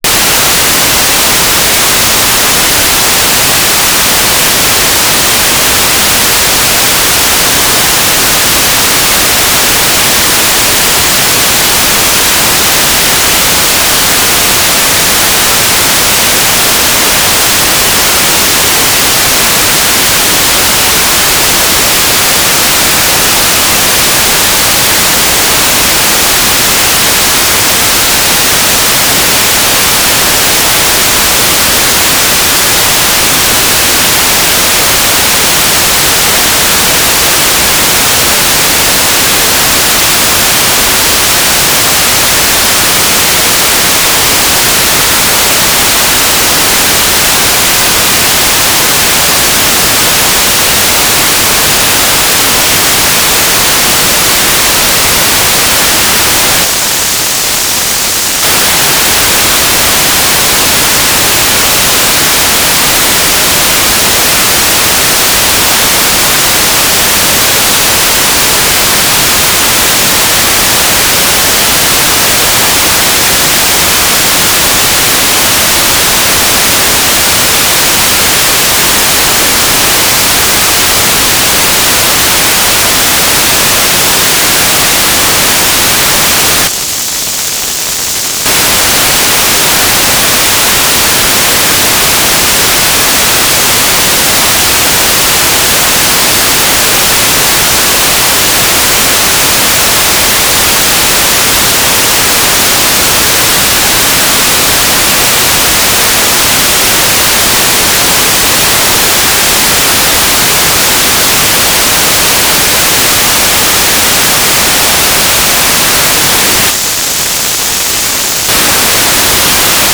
"transmitter_description": "Mode U - GMSK2k4 USP",
"transmitter_mode": "GMSK USP",